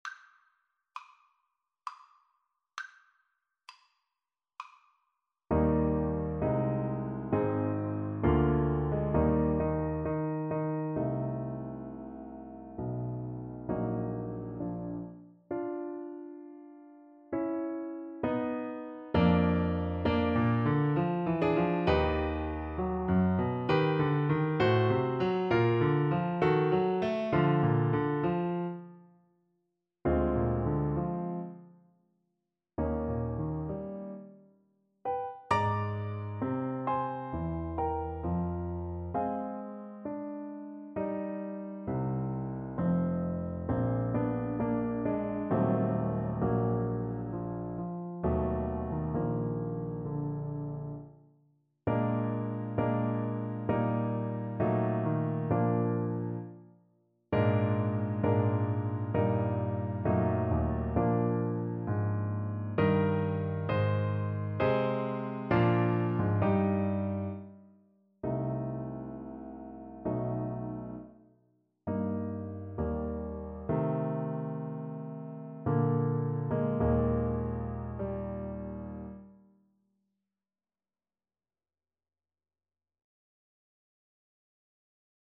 3/4 (View more 3/4 Music)
=66 Andante sostenuto
Classical (View more Classical Clarinet Music)